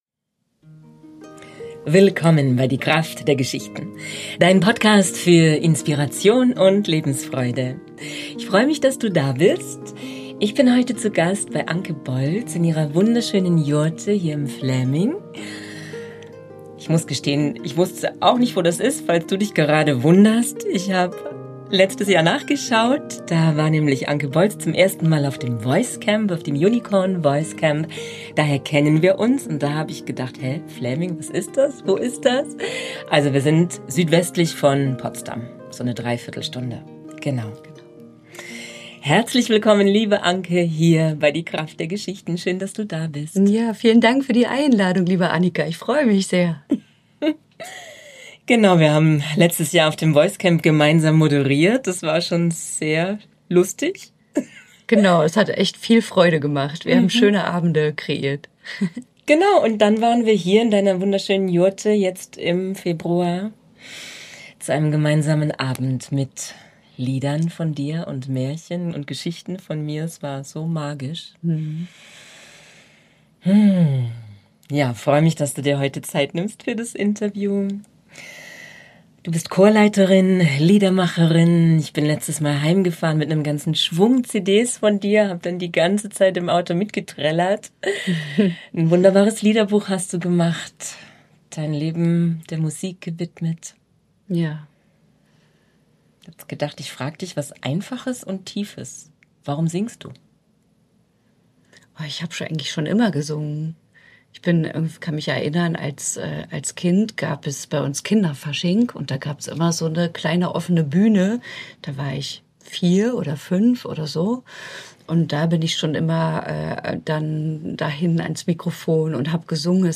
Es ist Erntezeit. Heute gibt es ein Interview für Dich.